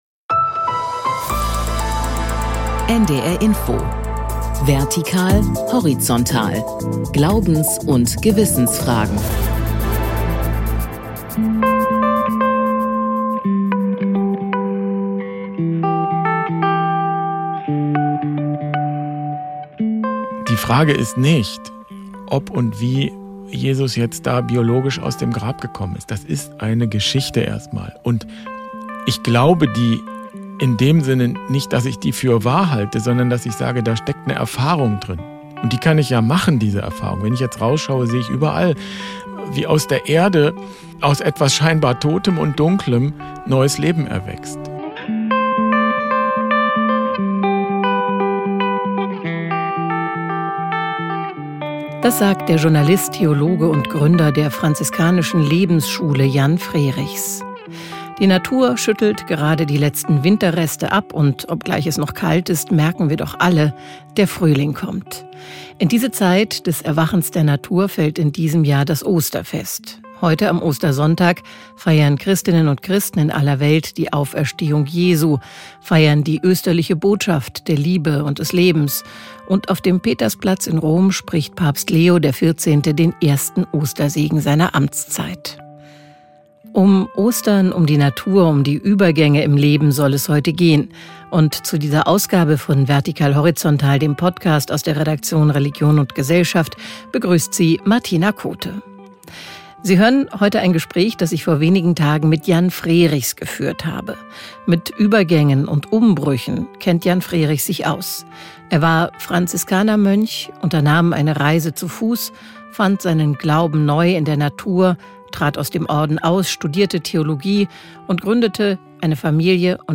Wie bereitet man sich auf Übergänge vor? Was sagt die Bibel zum Wandel? Darum geht’s in der Oster-Ausgabe von Vertikal Horizontal, dem Podcast aus der Redaktion Religion und Gesellschaft.